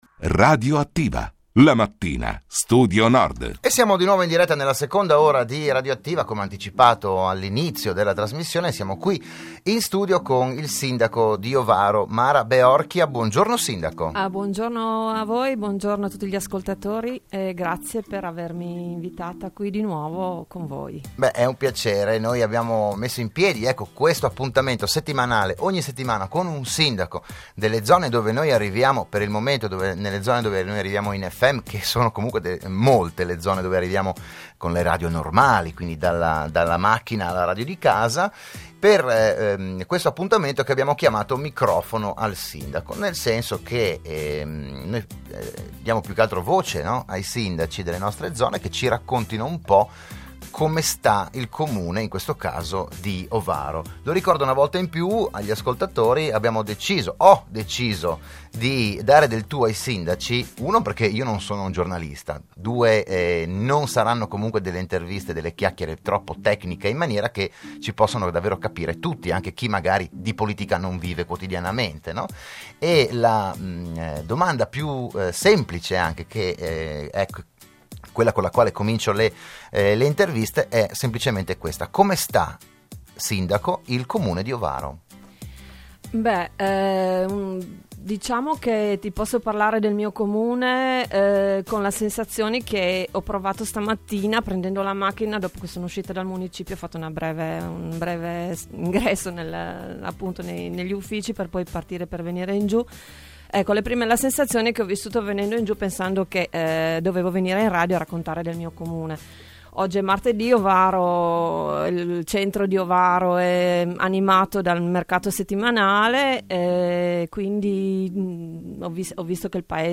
Periodicamente sarà ospite negli studi di RSN un sindaco del territorio per parlare della sua comunità, delle prospettive, degli eventi e quant’altro.